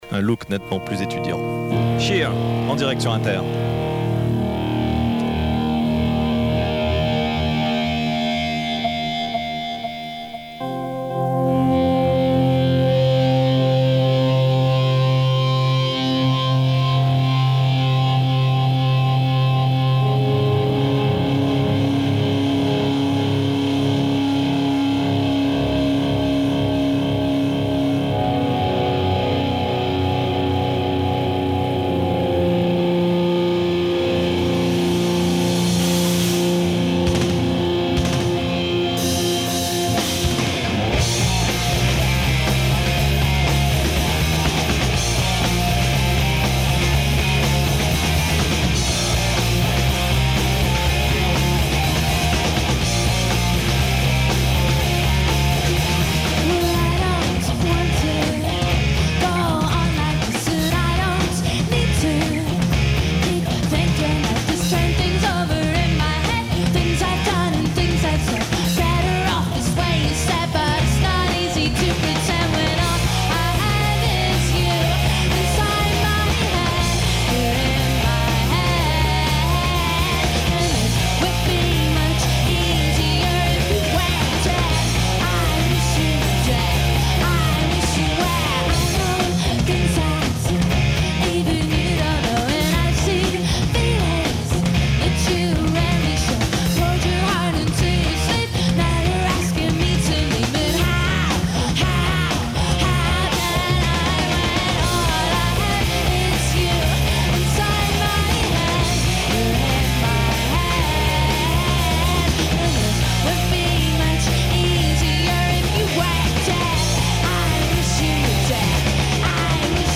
enregistrée le 28/05/1996  au Studio 105